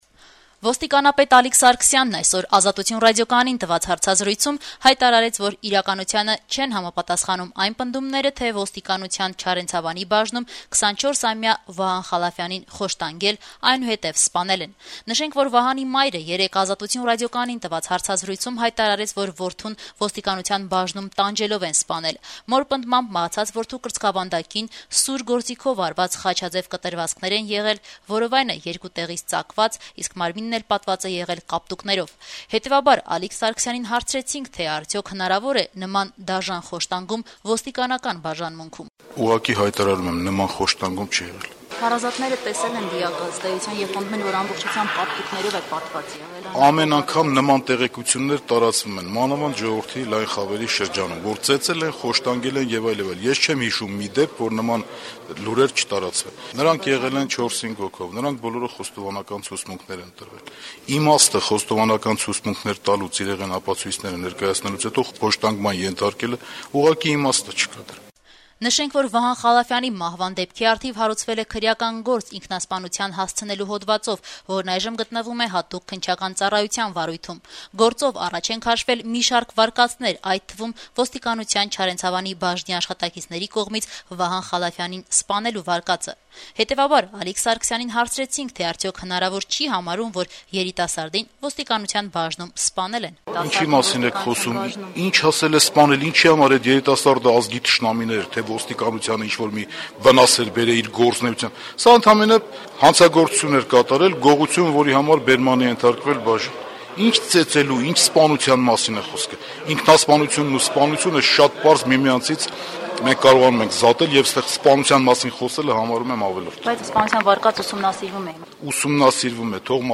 Հայաստանի ոստիկանապետ Ալիկ Սարգսյանը «Ազատություն» ռադիոկայանի հետ բացառիկ հարցազրույցում երեքշաբթի օրը կրկին հայտարարեց